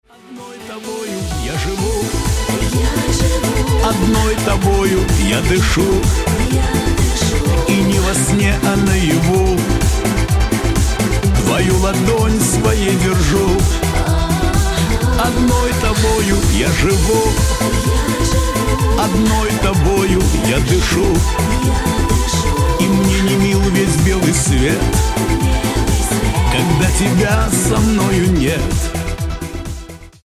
Рингтоны Ремиксы » # Шансон Рингтоны